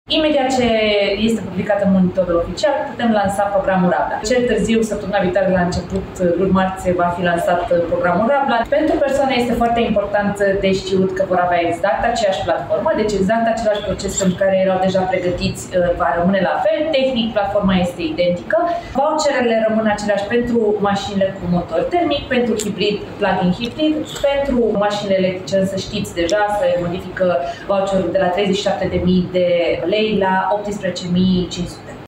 Programul Rabla pentru persoane fizice va fi lansat la începutul săptămânii viitoare, a anunțat ministra Mediului, Diana Buzoianu.